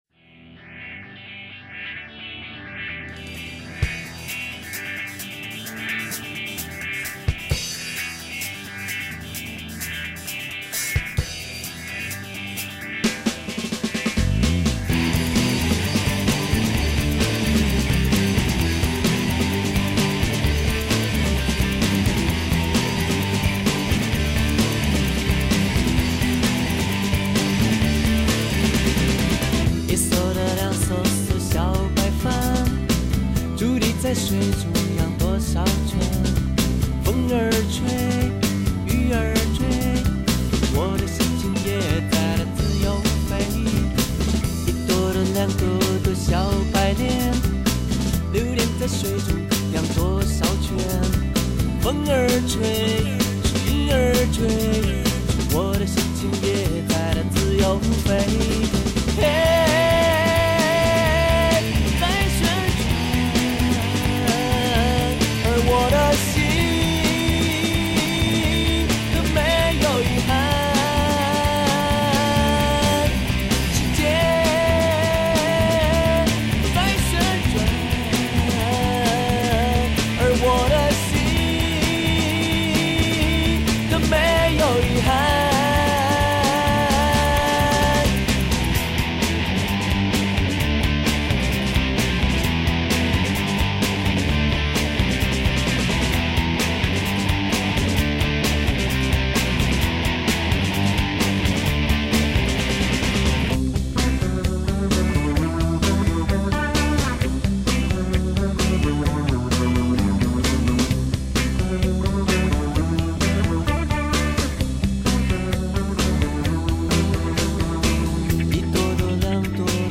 不做作、不追潮流、坚持自我、音乐风格多元化、 平民化、民族化、旋律动听、节奏稳实、跳跃、 动感十足